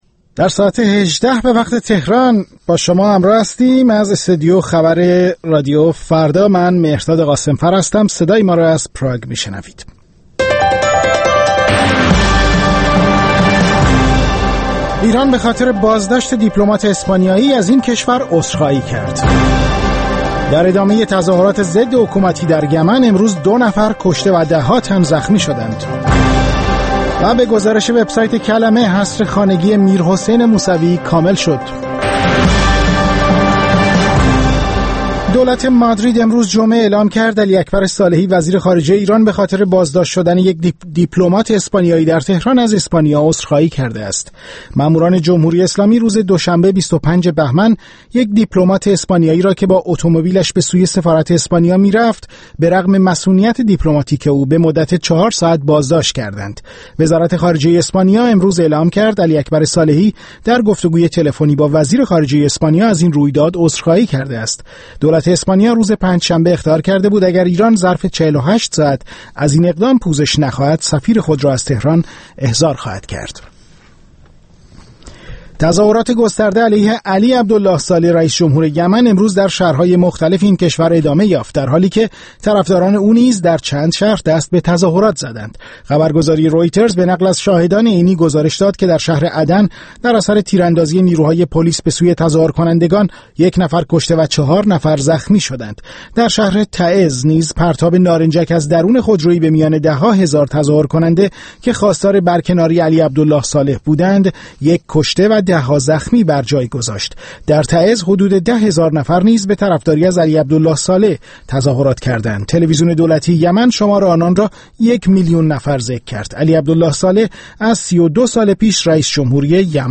در این برنامه زنده، به پرسش‌های شما و رادیوفردا پاسخ دهد.